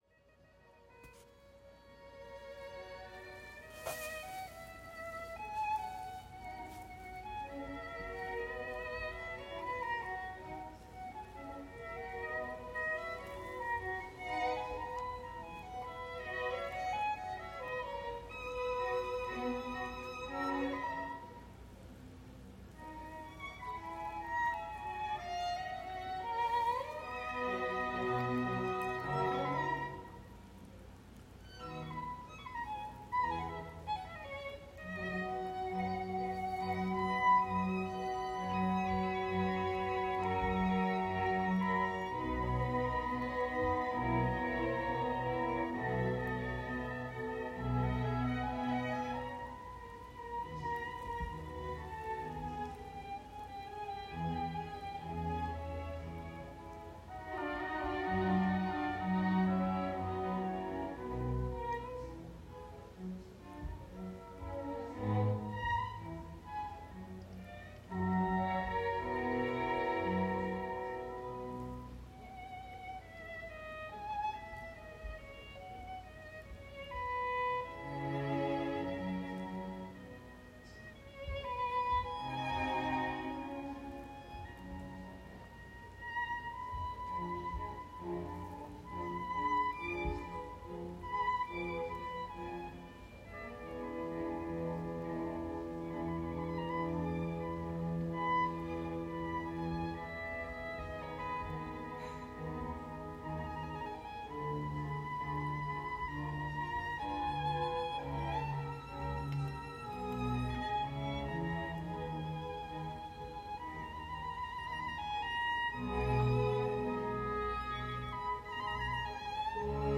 以 iPhone 12 Pro Max 256 GB 實際錄音下載
音質與收訊實測聲音表現，高音可延伸不失真，特色偏溫暖音色，耐聽與自然( 案例 99.7 愛樂電台 )
以-iPhone-12-Pro-Max256GB-實際錄音.wav